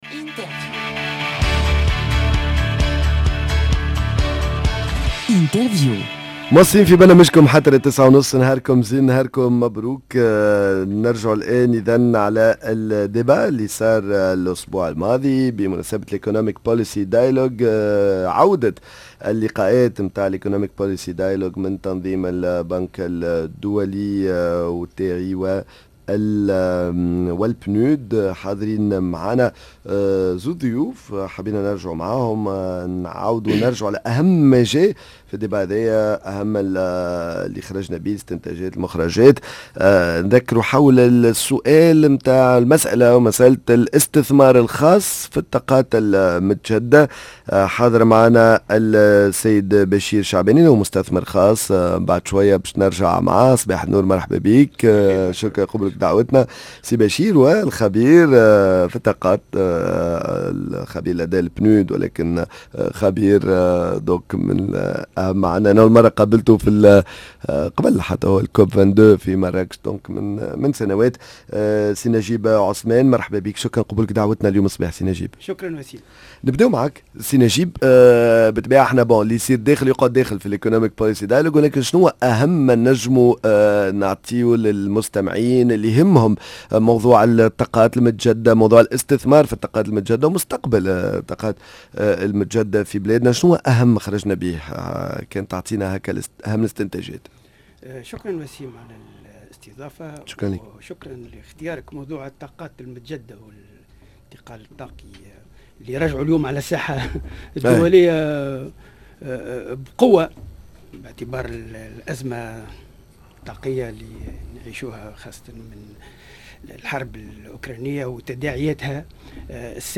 Débat autour de la question de l'investissement privé dans la production de l'énergie (énergies renouvelables)